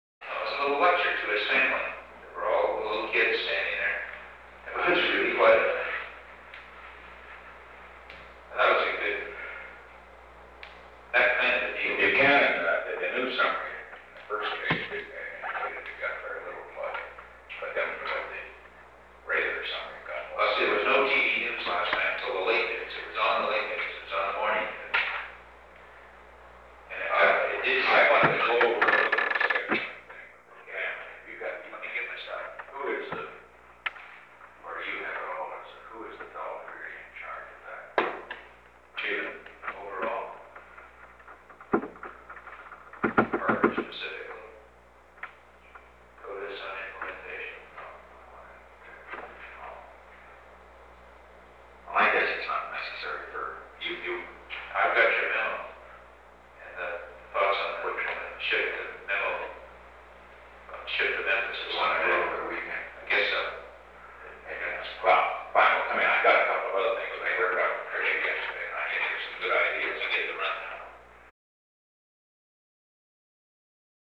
Conversation: 781-005
Recording Device: Oval Office
On September 18, 1972, President Richard M. Nixon and H. R. ("Bob") Haldeman met in the Oval Office of the White House from 10:16 am to 10:18 am. The Oval Office taping system captured this recording, which is known as Conversation 781-005 of the White House Tapes.